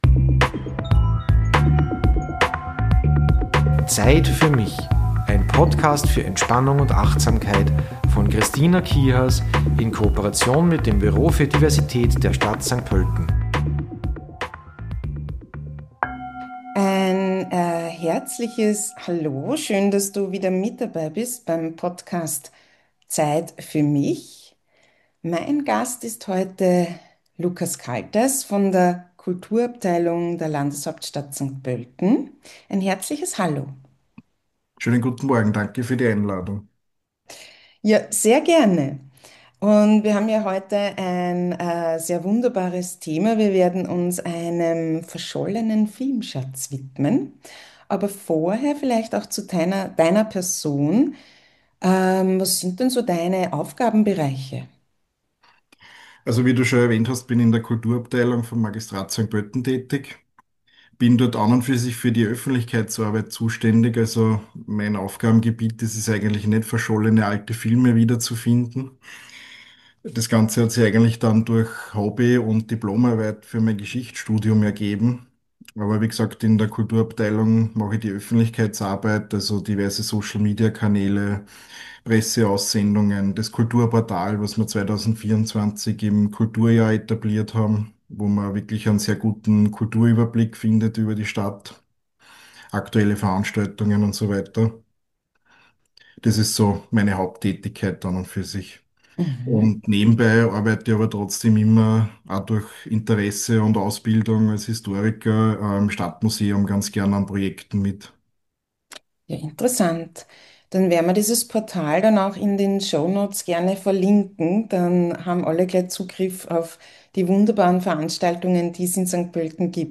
Im Entspannungsteil erwartet dich der "Summende Bienenatem" gepaart mit einer Übung zur Nackendehnung.